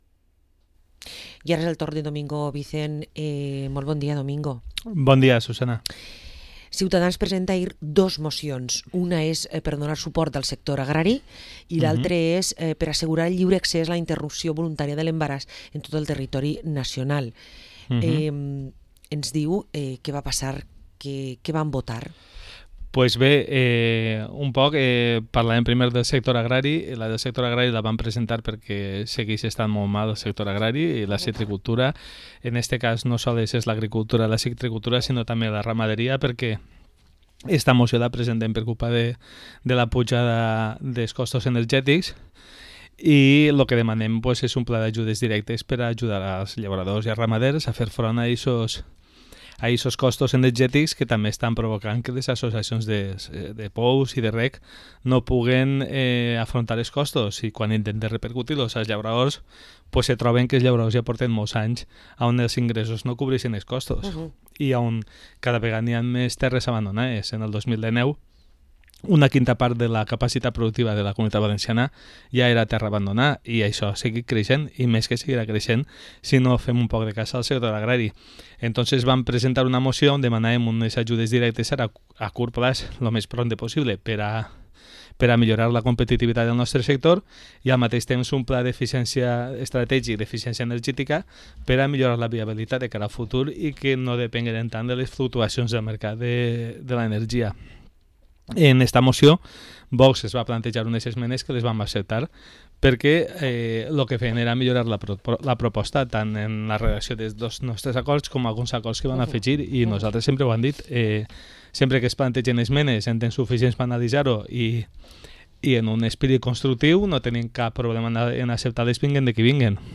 Domingo Vicent s’apropat a Radio Vila-real, al programa ‘Protagonistes» per a explicar-los a tots vosaltres les dos mocions que el seu grup polític Ciutadans va presentar ahir al plé ordinari a l’Ajuntament de Vila-real. Ha destacat que sobre la moció presentada per a un lliure abortament, PSOE I COMPROMÍS van votar a favor però va quedar sorprés per l’abstenció dels grups PP i UNIDES PODEM.